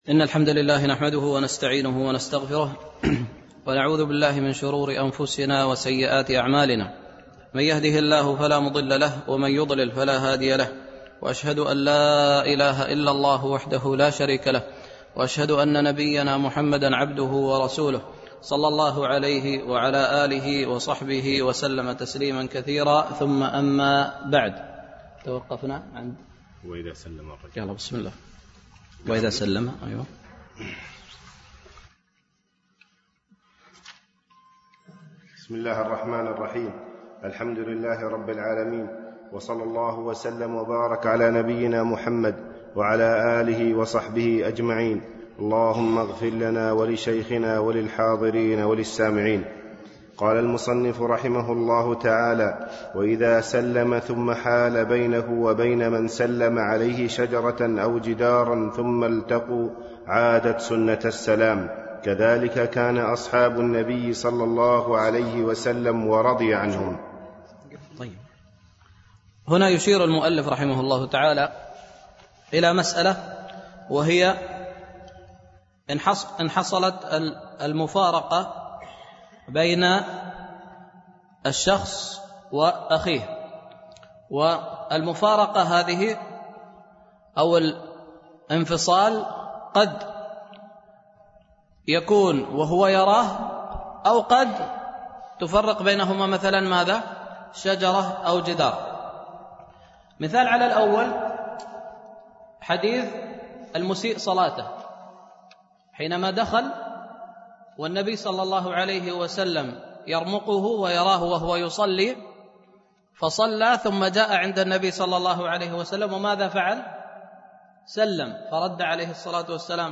دورة الإمام مالك العلمية الثالثة بدبي
دروس مسجد عائشة (برعاية مركز رياض الصالحين ـ بدبي)